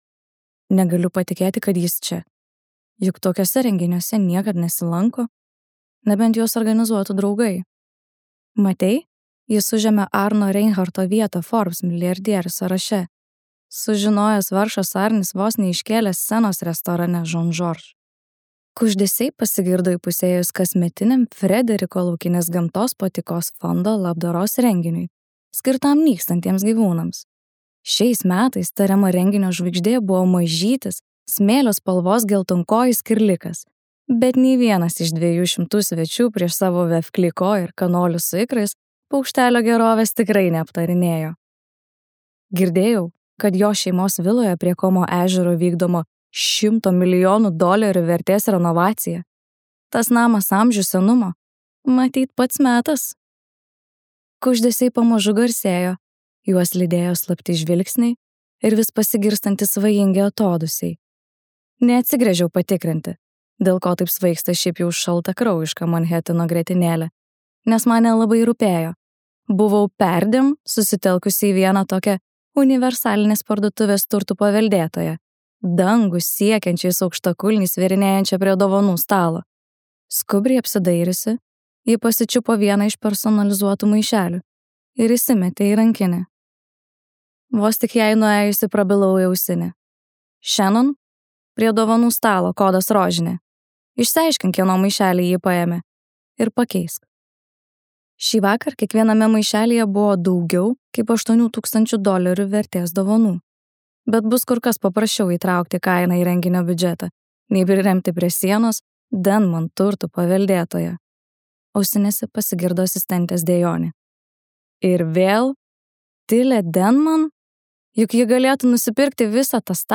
Anos Huang audioknyga „Rūstybės karalius“ – pirmoji serijos „Kings of Sin“ dalis. Tai istorija apie suplanuotą santuoką tarp renginių organizatorės Vivianos ir milijardieriaus Dantės.